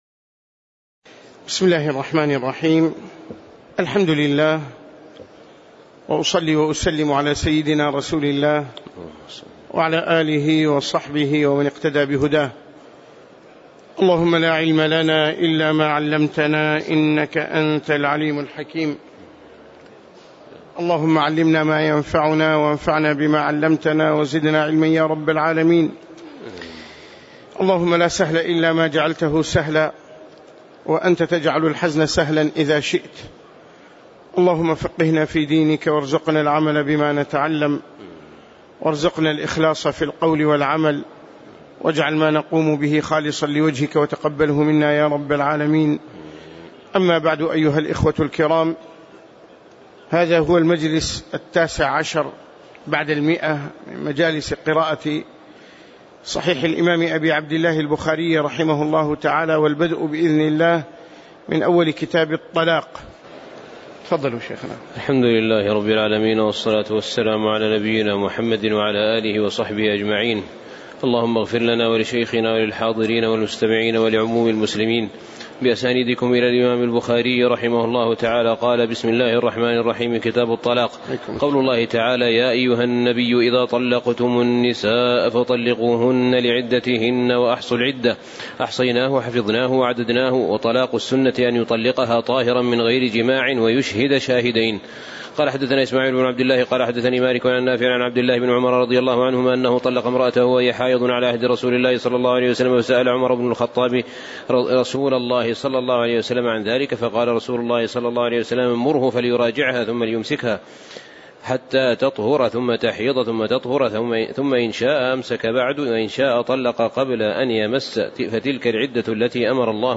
تاريخ النشر ١٨ شعبان ١٤٣٨ هـ المكان: المسجد النبوي الشيخ